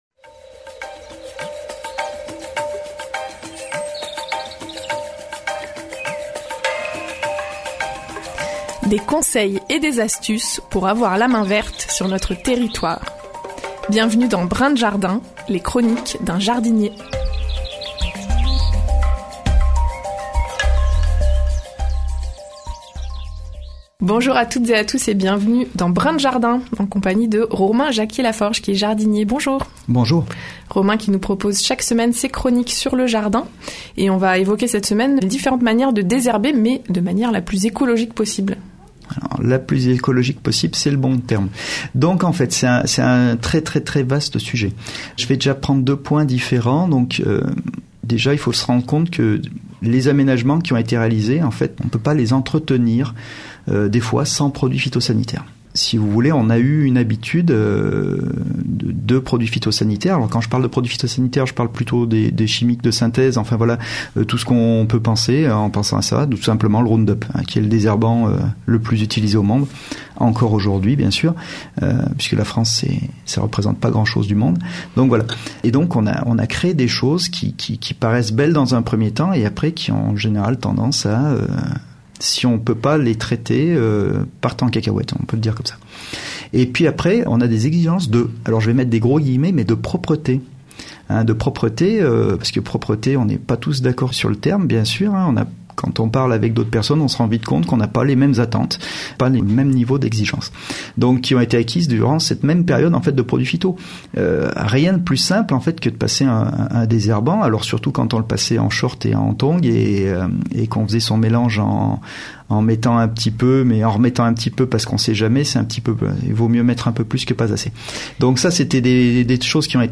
La chronique jardin hebdomadaire sur les ondes de Radio Royans Vercors